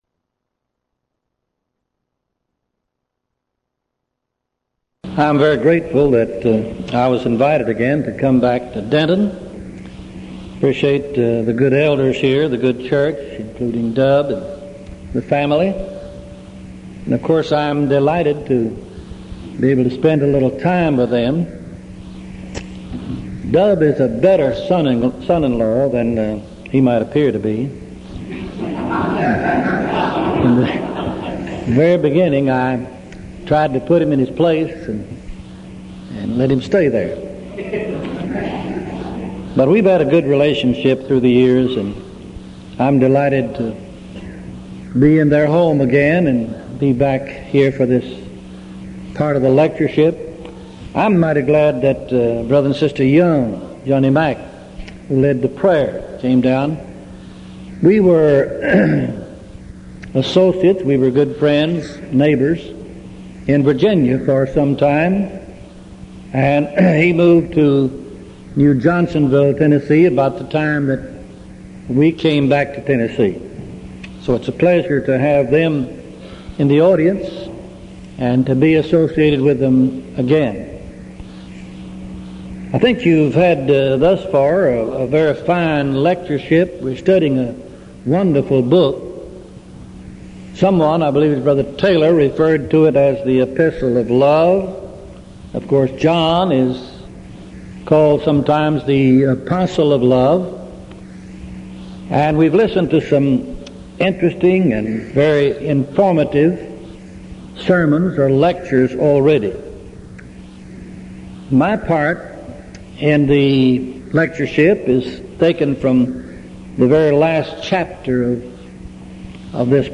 Event: 1987 Denton Lectures
lecture